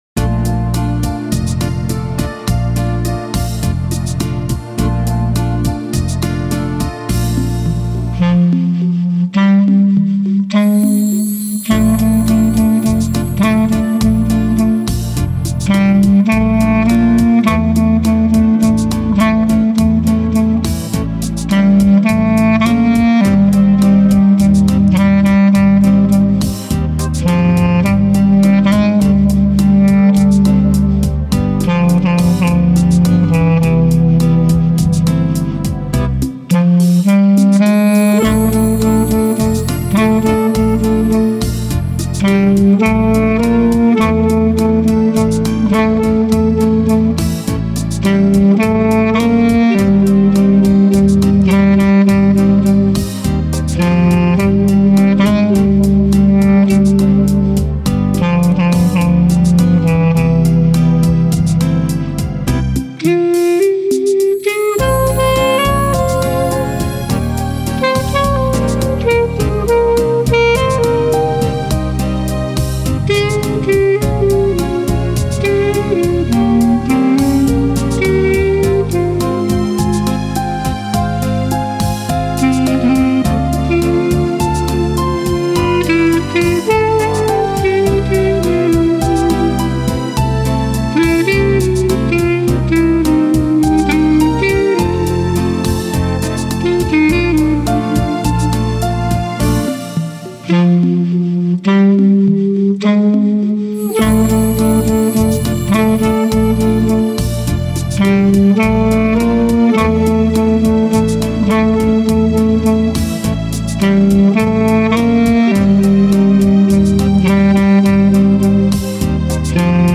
Жанр: easy listening